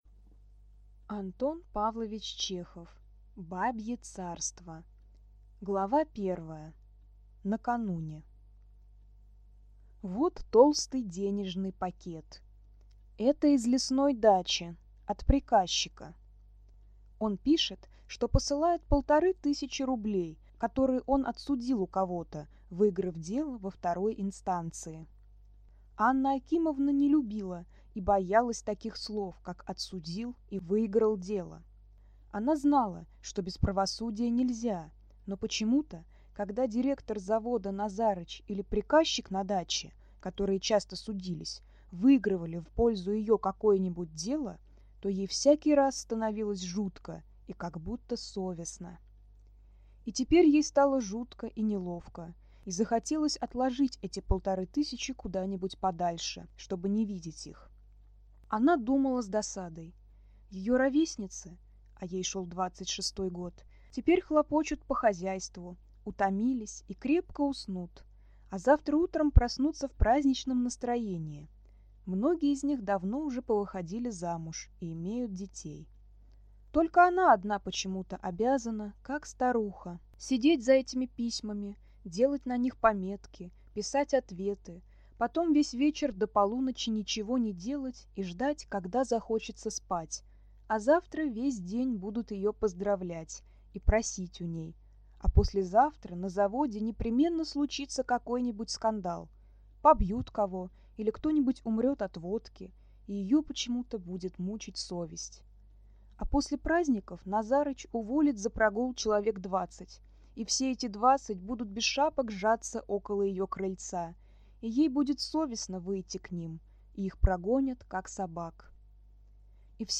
Аудиокнига Бабье царство | Библиотека аудиокниг